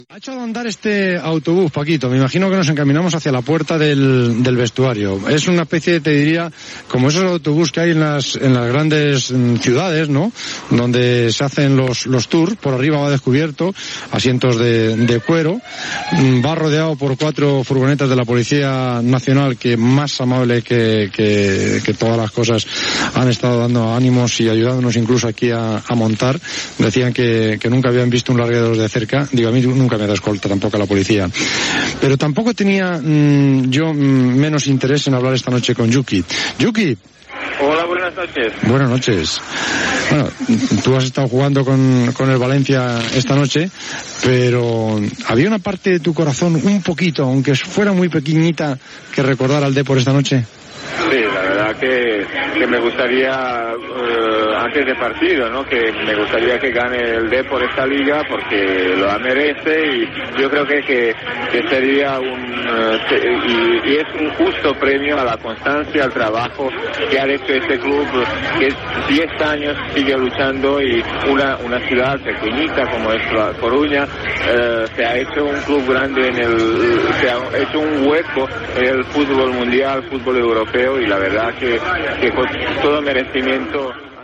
Transmissió de la celebració del títol de lliga de primera divsió de futbol masculí per part del Deportivo de La Coruña, a la temporada 1999-2000.
Esportiu